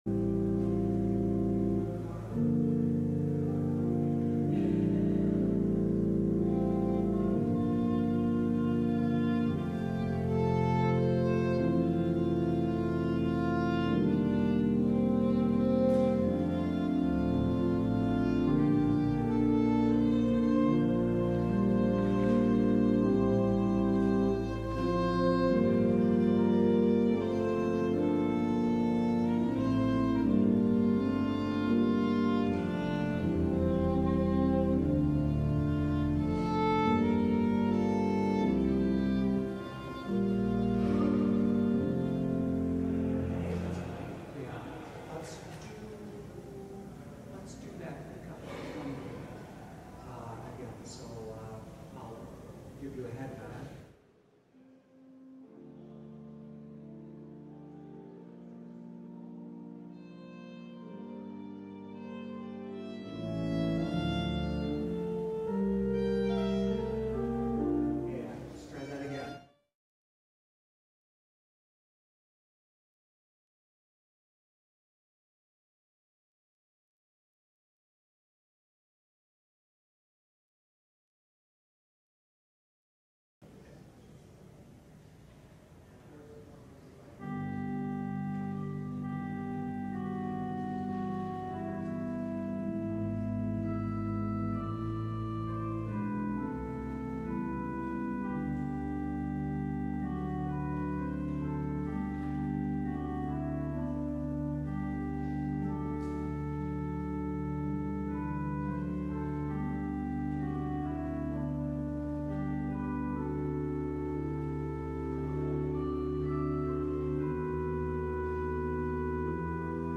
LIVE Morning Worship Service - From Every Nation